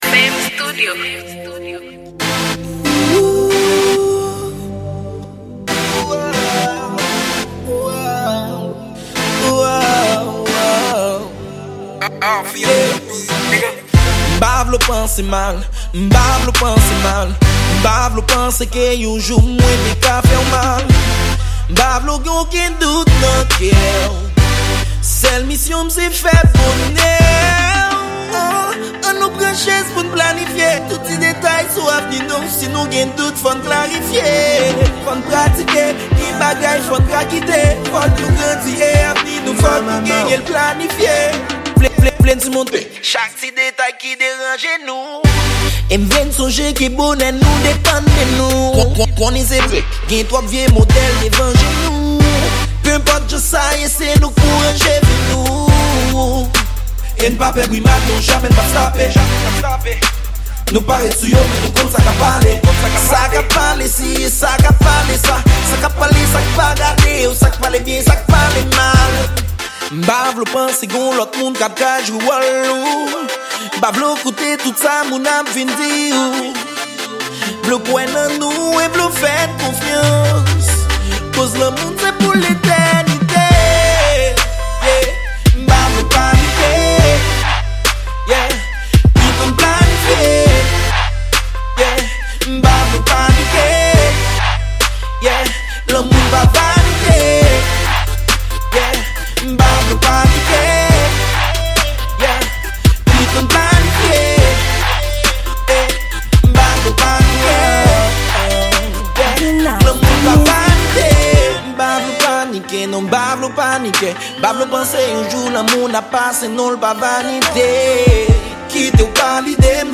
Genre: R&B